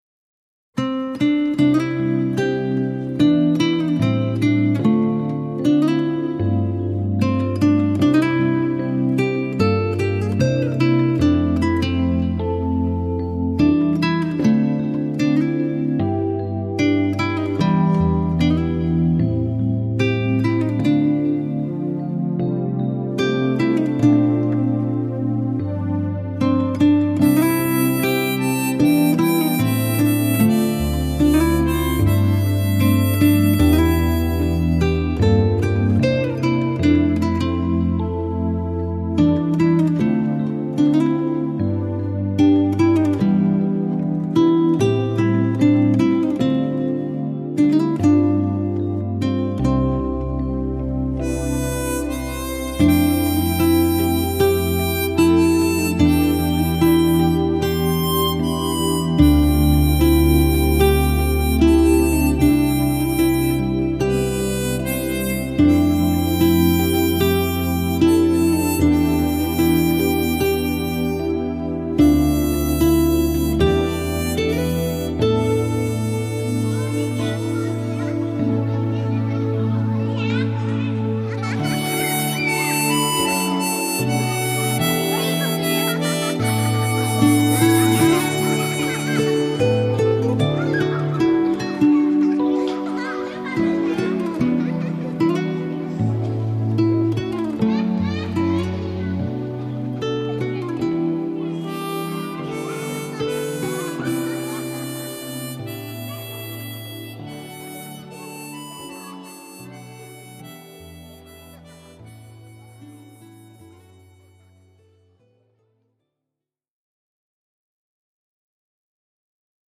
清新自然 古朴淡雅
整张专辑曲调清新，曲风古朴淡雅。整体编排别具一格，民族器乐和电子合成融揉的自然，和谐。配以空灵缥缈的古语和声，云里雾里。